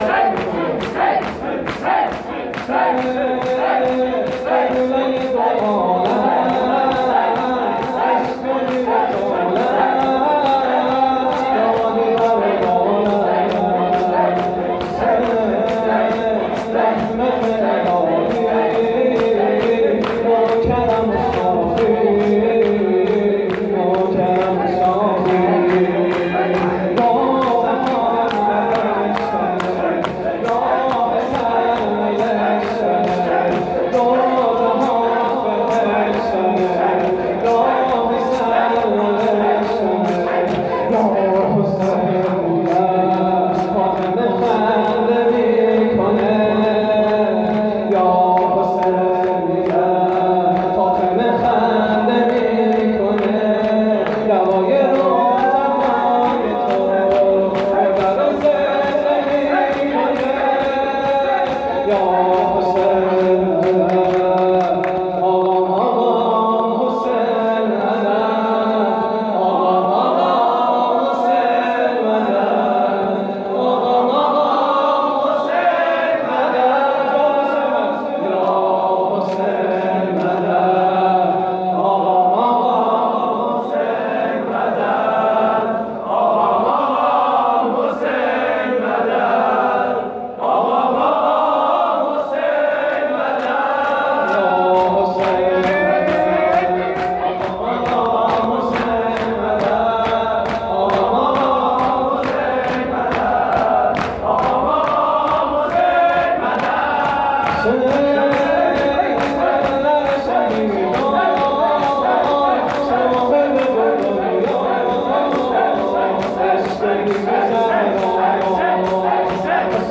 هیئت و کانون منتظران موعود - شور